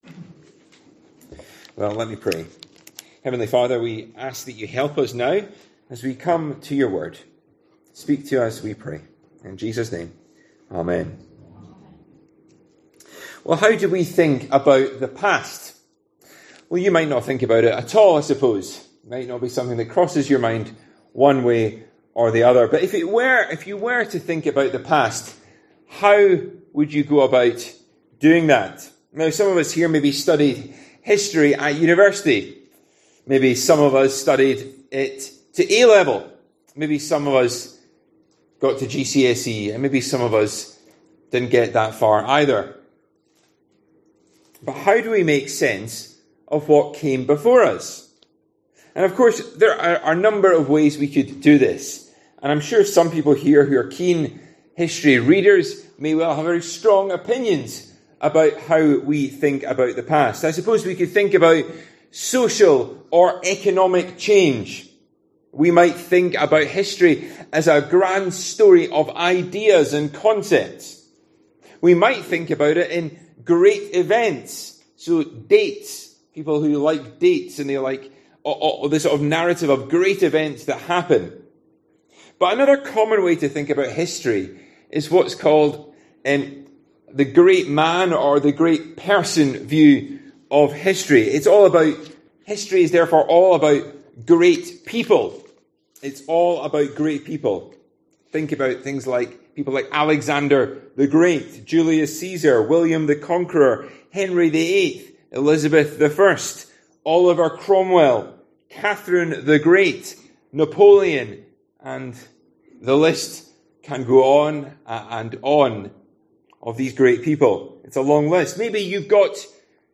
Easter | Sermons | Trinity Church Liphook